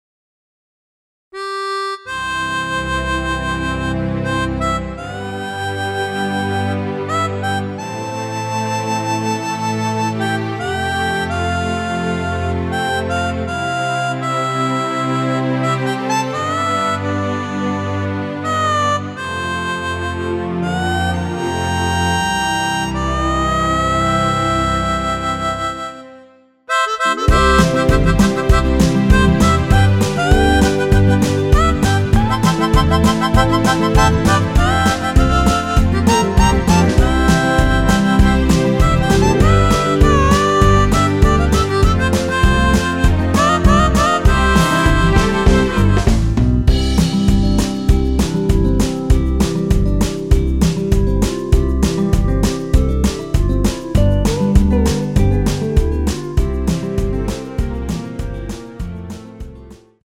원키 멜로디 포함된 MR 입니다.(미리듣기 확인)
앞부분30초, 뒷부분30초씩 편집해서 올려 드리고 있습니다.
중간에 음이 끈어지고 다시 나오는 이유는